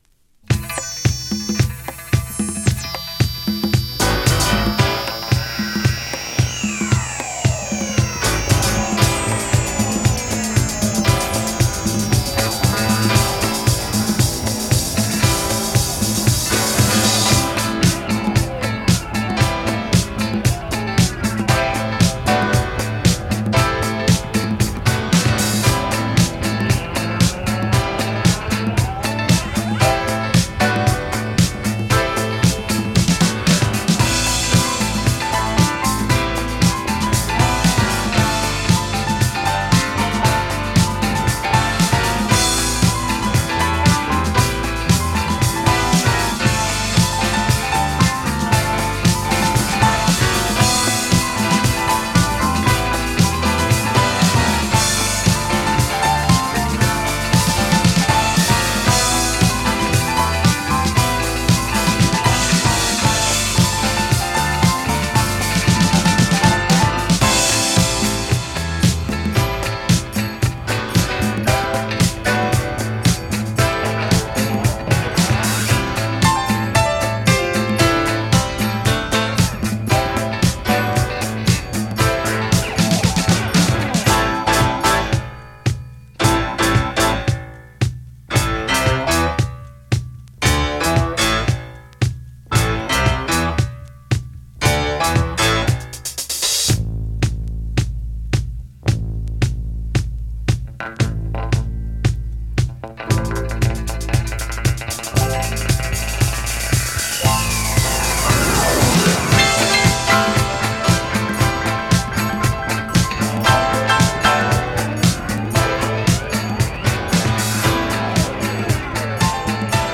DISCO
RARE MODERN SOUL〜BOOGIE & DOPE GRO…